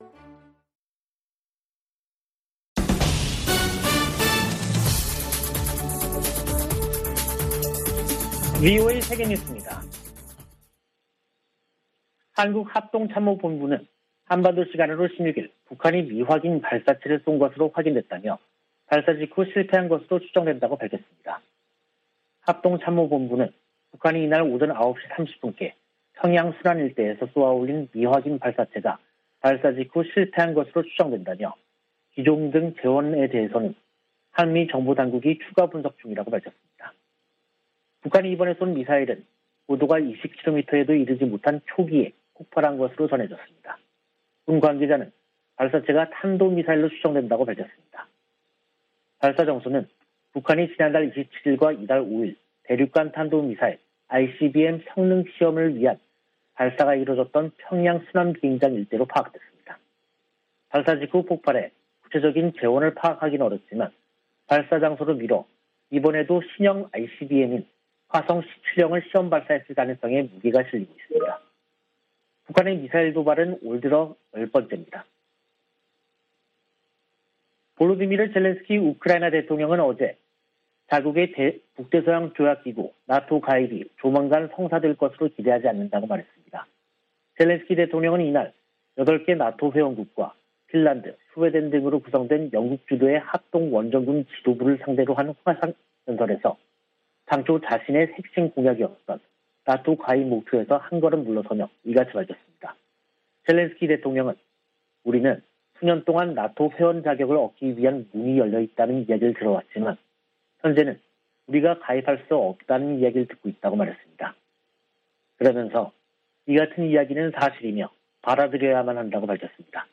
VOA 한국어 간판 뉴스 프로그램 '뉴스 투데이', 2022년 3월 16일 2부 방송입니다. 한국 합동참모본부는 북한이 16일 평양 순안 일대에서 미확인 발사체를 발사했으나 실패한 것으로 추정된다고 밝혔습니다. 미 국무부는 북한의 탄도미사일 시험발사를 규탄하고, 대화에 나서라고 촉구했습니다. 유럽연합(EU)은 북한의 최근 탄도미사일 발사와 관련해 추가 독자 제재 부과를 검토할 수 있다는 입장을 밝혔습니다.